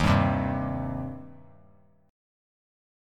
Eb6 Chord
Listen to Eb6 strummed